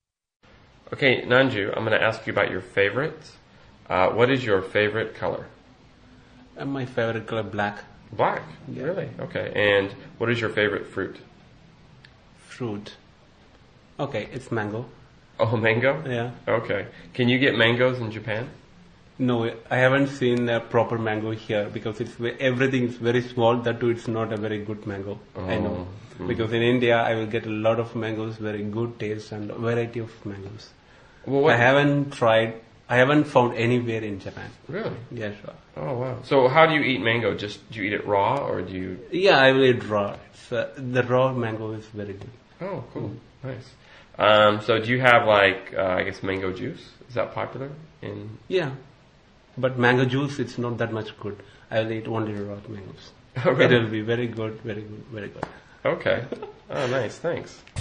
英语初级口语对话正常语速02：水果（MP3+lrc）